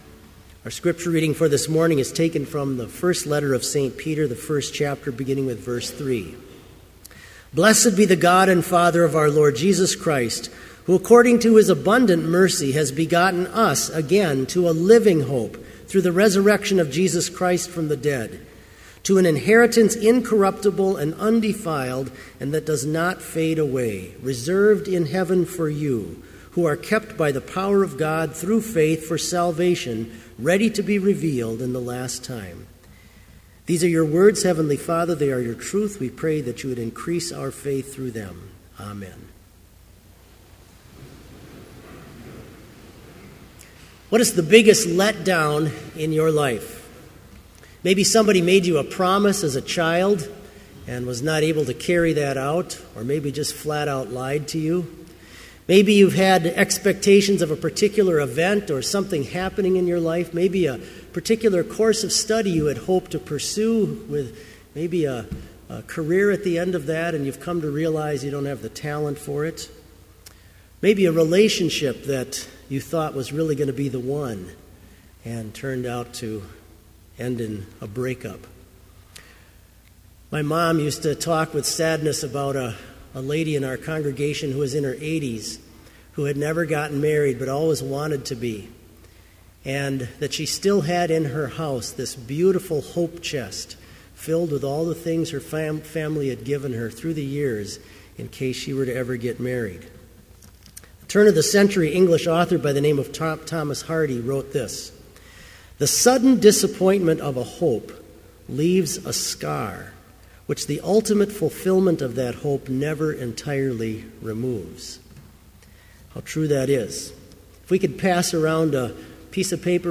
Complete Service
• Prelude
• Homily
This Chapel Service was held in Trinity Chapel at Bethany Lutheran College on Friday, April 25, 2014, at 10 a.m. Page and hymn numbers are from the Evangelical Lutheran Hymnary.